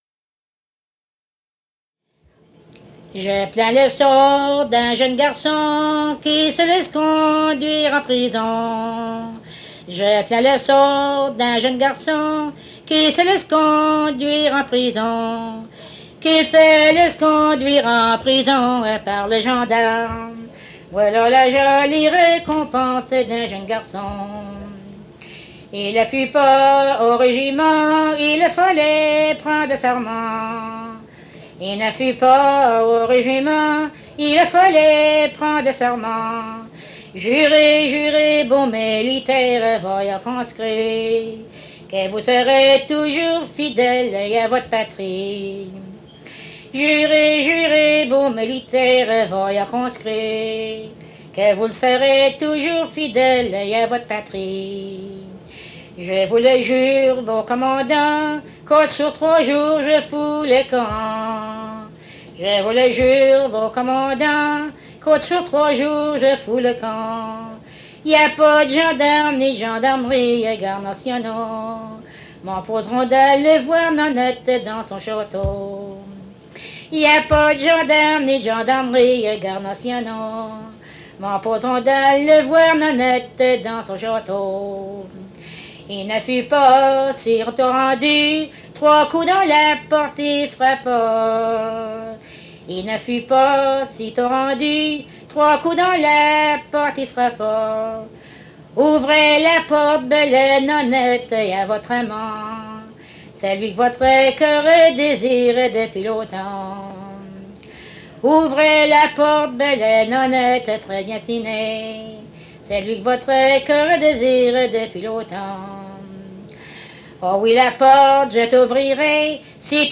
Emplacement Cap St-Georges